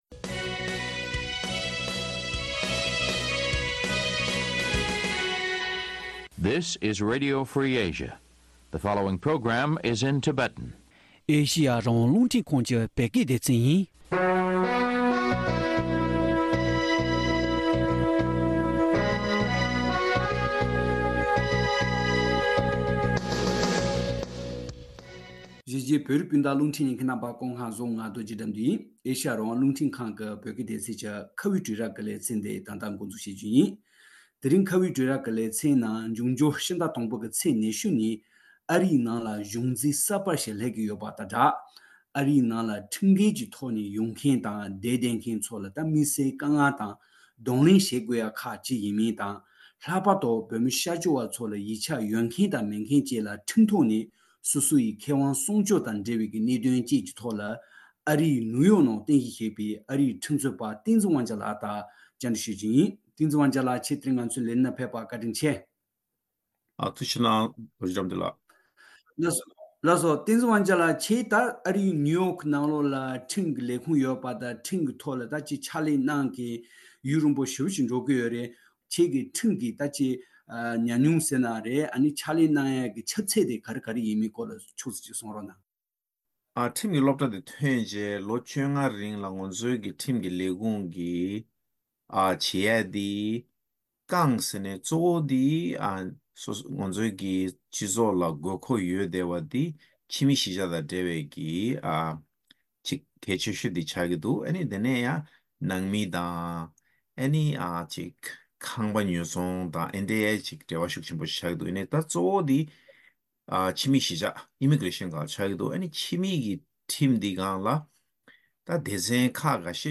བགྲོ་གླེང་ཞུས་པ།